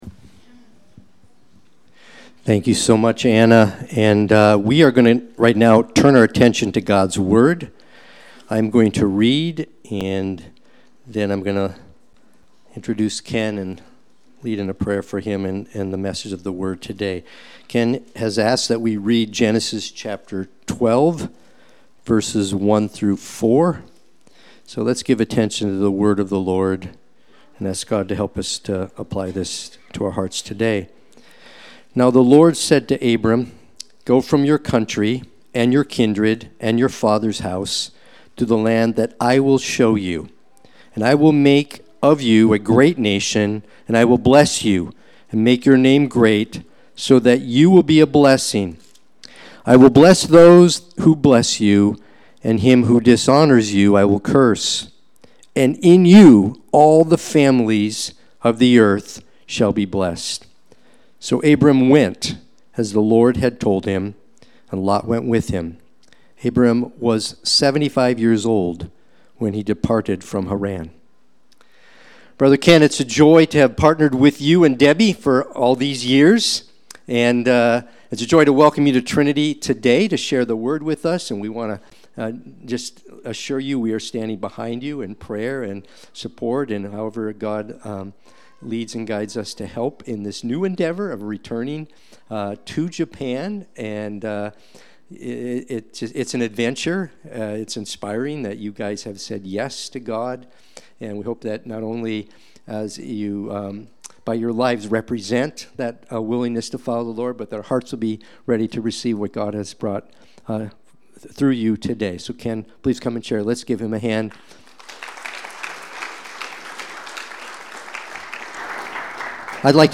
Watch the replay or listen to the sermon.
Sunday-Worship-main-9725.mp3